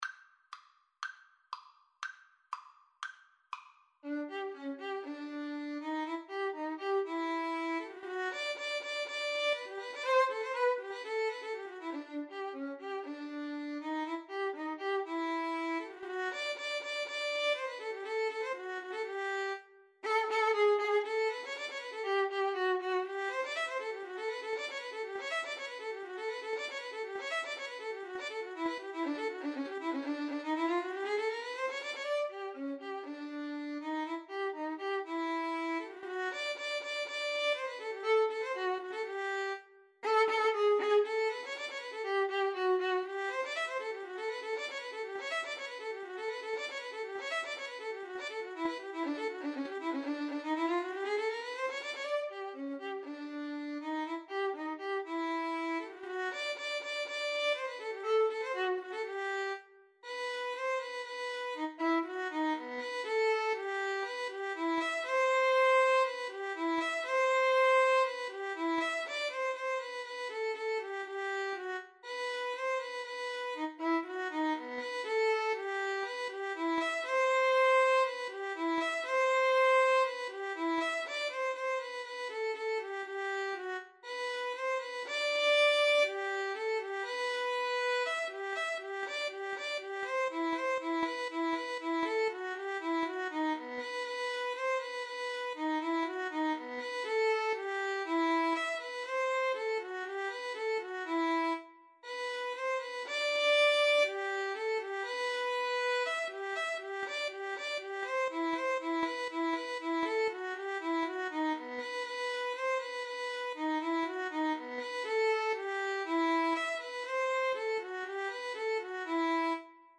Play (or use space bar on your keyboard) Pause Music Playalong - Player 1 Accompaniment reset tempo print settings full screen
G major (Sounding Pitch) (View more G major Music for Violin Duet )
Rondo - Allegro (View more music marked Allegro)
Classical (View more Classical Violin Duet Music)